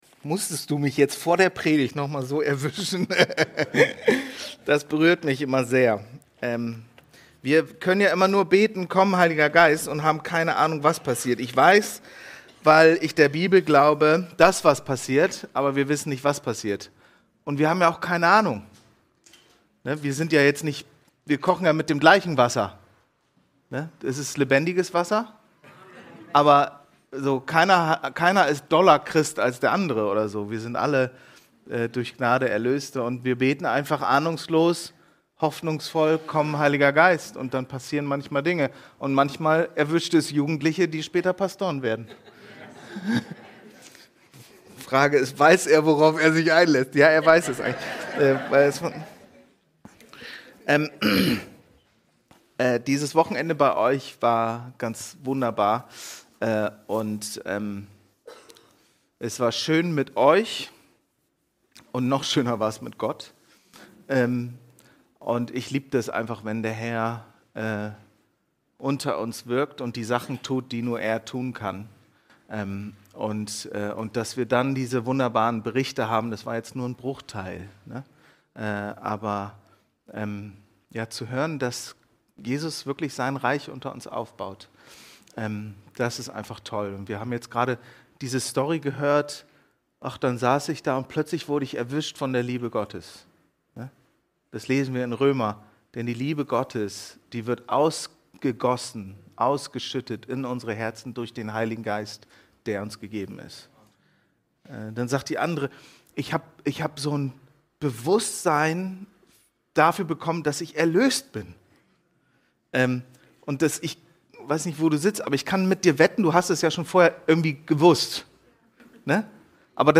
Diese Folge wurde bei der Anskar-Konferenz in Wetzlar übertragen.